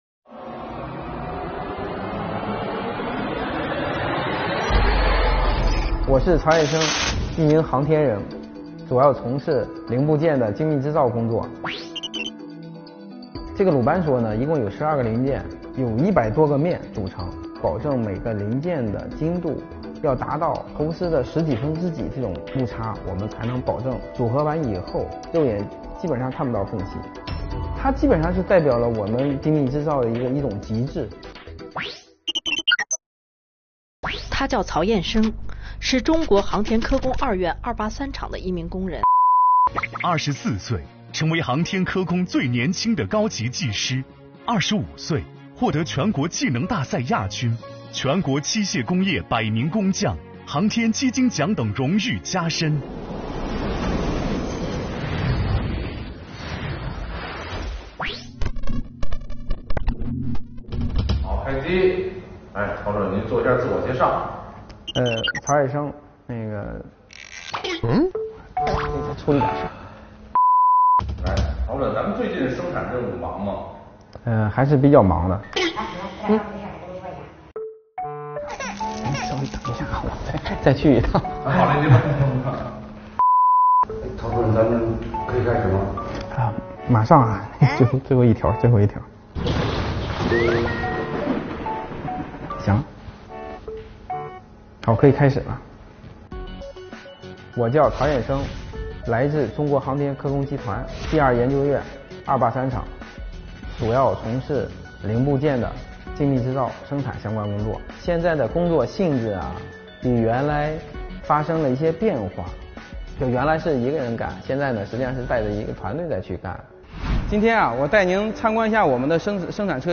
由央视频、全总新闻中心、CGTN联合推出的“劳动最光荣——前方高能”原创匠心微纪录片致敬这个时代最美丽的人，向国人及世界展现奋斗在我国各行各业里平凡却闪光的人物，传递中国精神与中国力量，弘扬劳模精神、劳动精神、工匠精神！本期我们带您了解航天制造业领域中一位培养了多位大国工匠的年轻“大师”↓